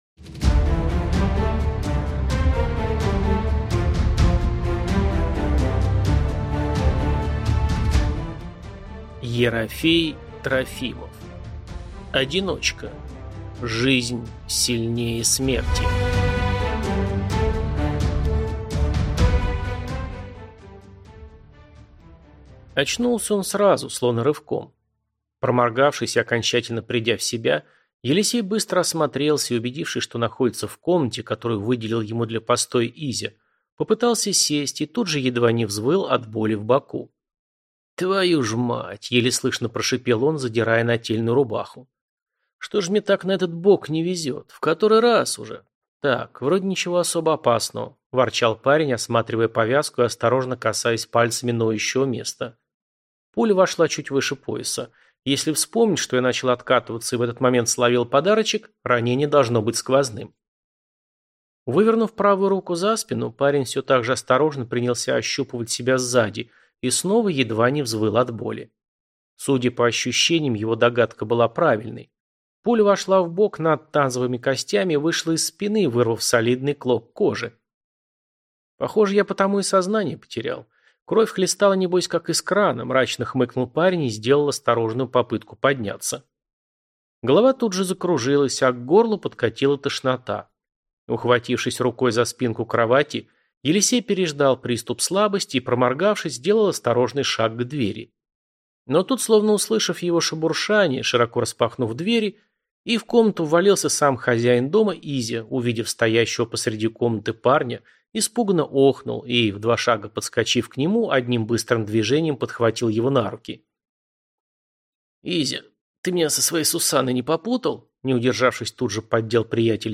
Аудиокнига Одиночка. Честь и кровь: Жизнь сильнее смерти. Честь и кровь. Кровавая вира | Библиотека аудиокниг